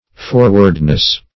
Forwardness \For"ward*ness\, n.